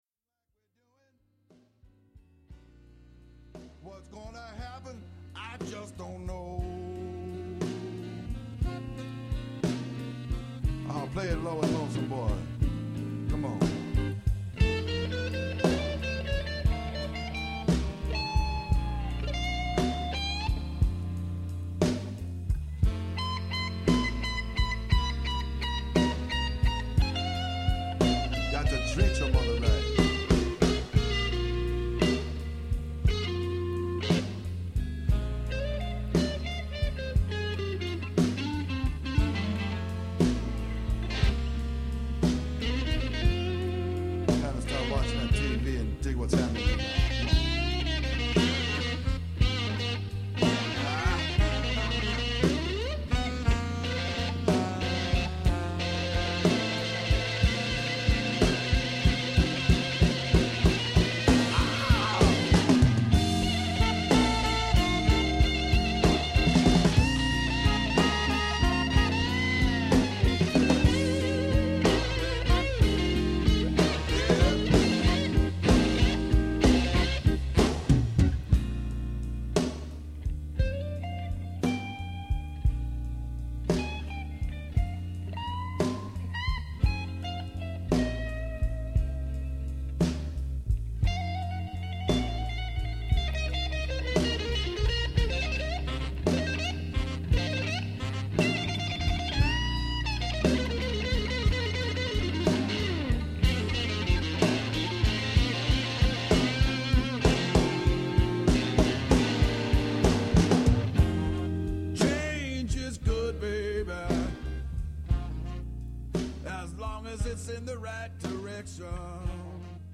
(STUDIO)
vocals